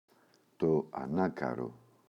ανάκαρο, το [a’nakaro]